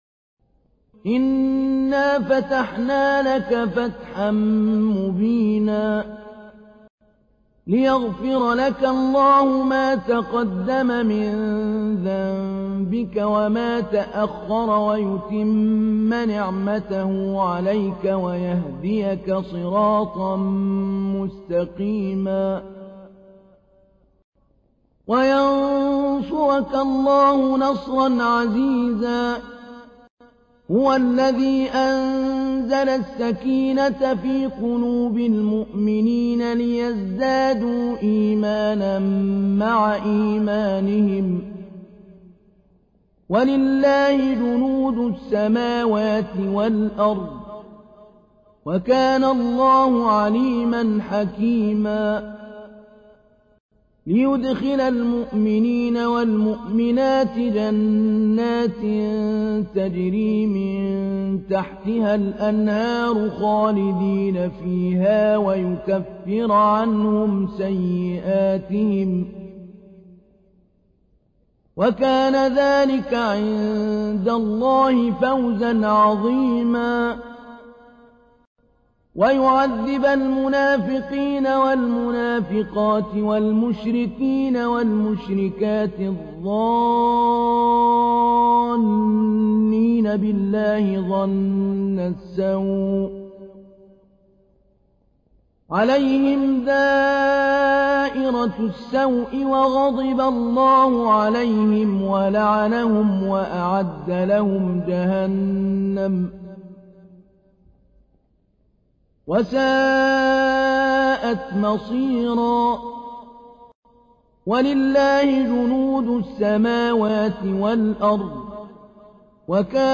تحميل : 48. سورة الفتح / القارئ محمود علي البنا / القرآن الكريم / موقع يا حسين